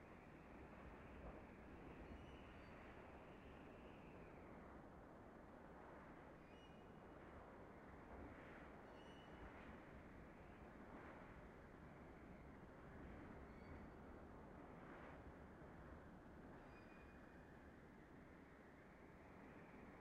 sfx_amb_map_zoomedin_mountain.ogg